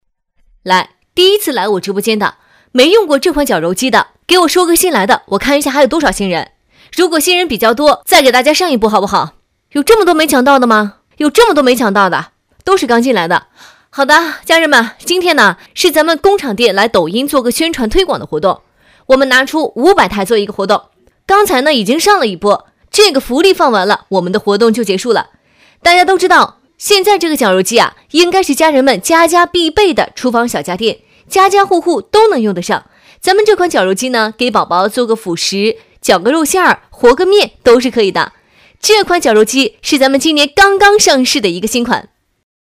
女3号